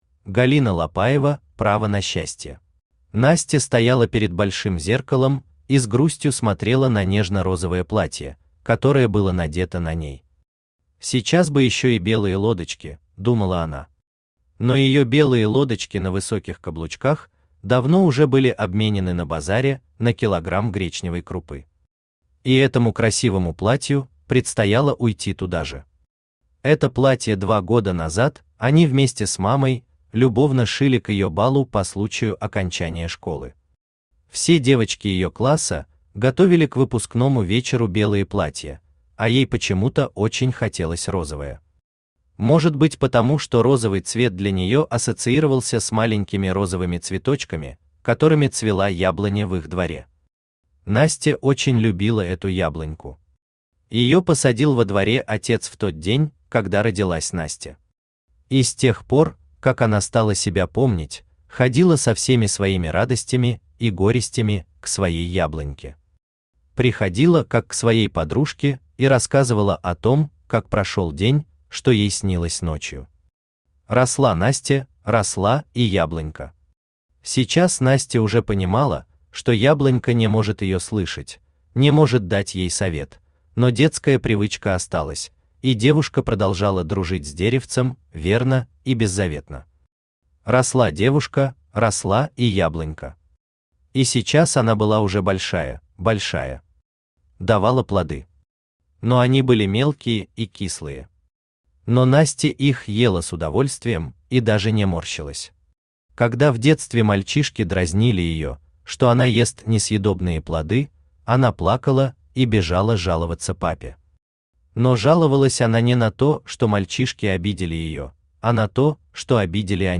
Аудиокнига Право на счастье | Библиотека аудиокниг
Aудиокнига Право на счастье Автор Галина Ивановна Лапаева Читает аудиокнигу Авточтец ЛитРес.